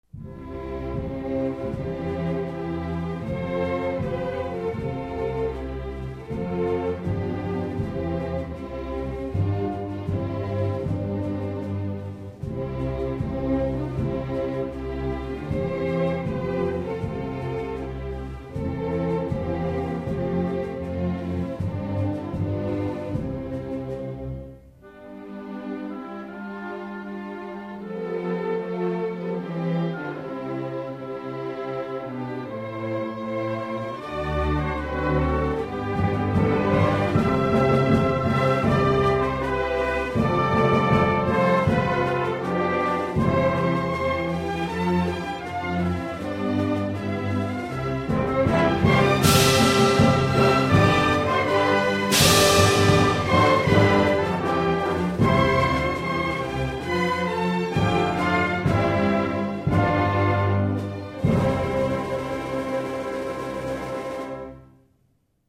比较柔和的版本